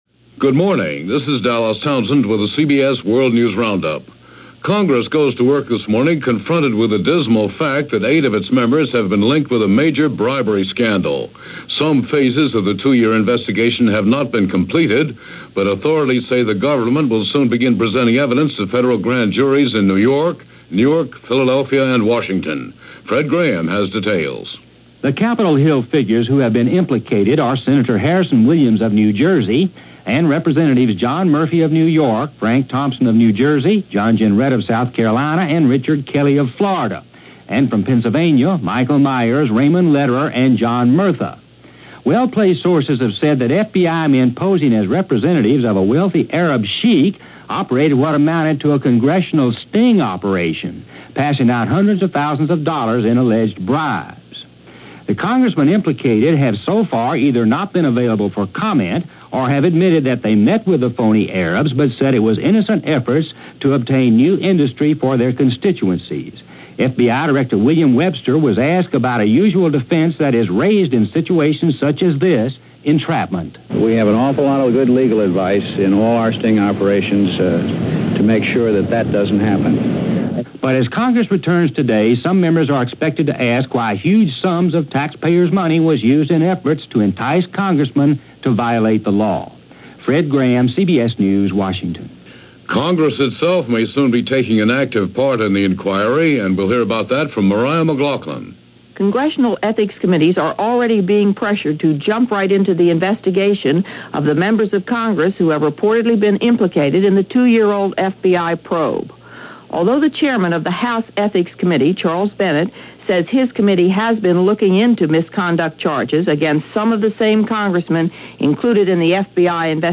CBS World News Roundup